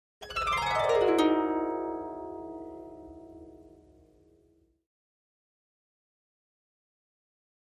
Harp, Medium Strings, 7th Chord, Short Descending Gliss, Type 2